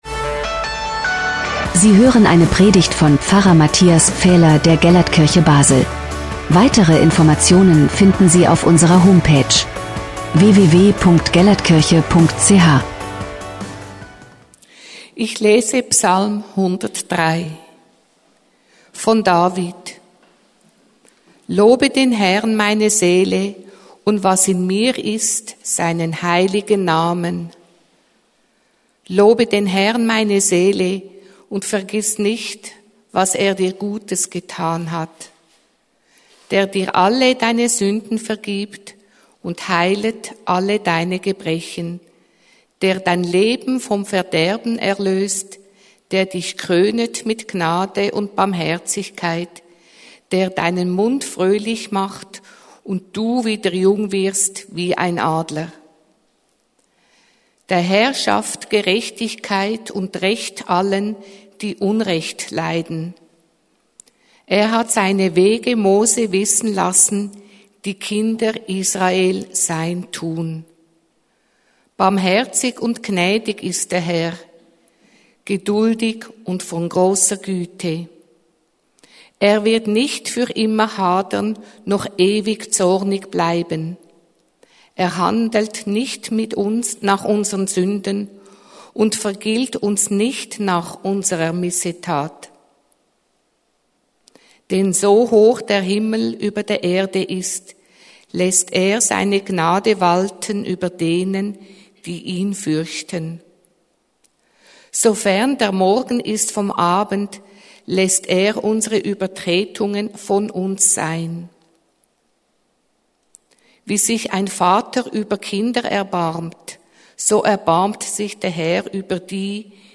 Letzte Predigt